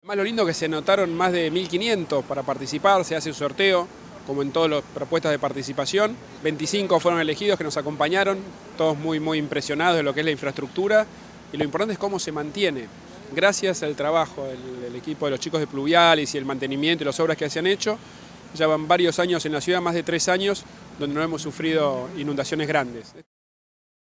Finalizado el recorrido, Rodríguez Larreta destacó el proceso participativo que acompañó a la actividad al señalar que “se anotaron más de 1500 personas, se hizo un sorteo como en todas las propuestas de participación, y 25 fueron elegidas”.